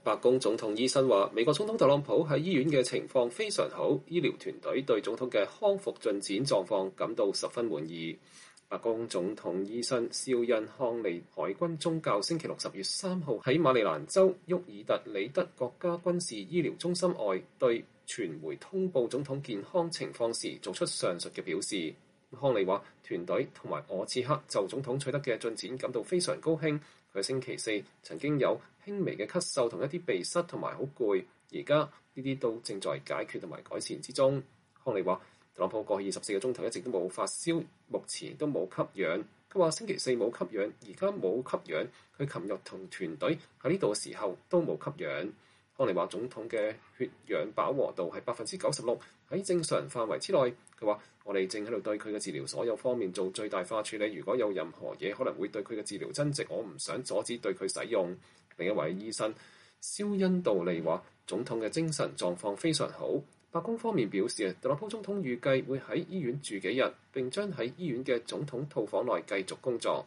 白宮總統醫生肖恩·康利海軍中校10月3日在馬里蘭州沃爾特-里德國家軍事醫療中心外面向媒體通報總統健康狀況。